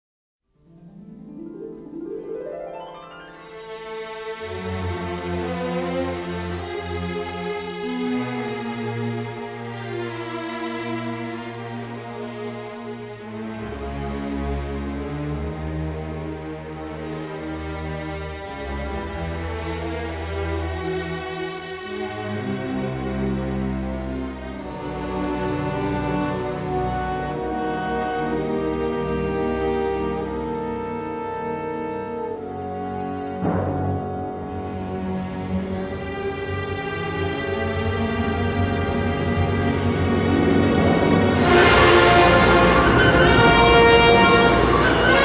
and a classic symphonic score.